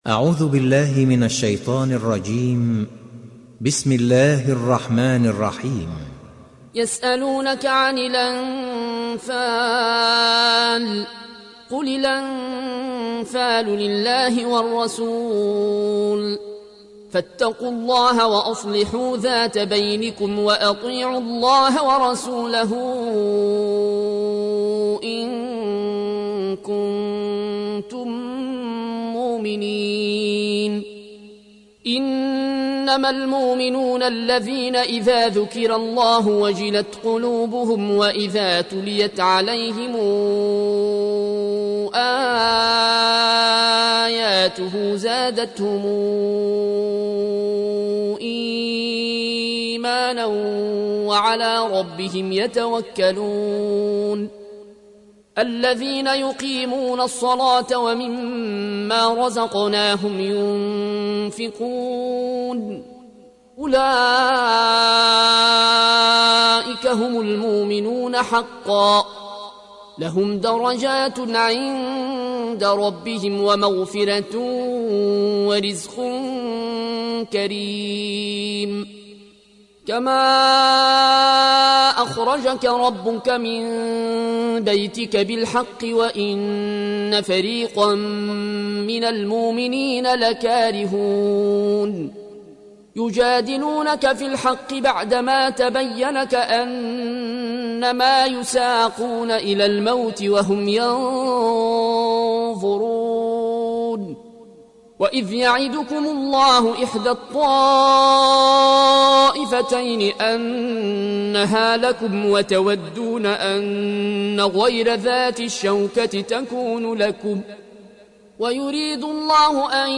(Riwayat Warsh)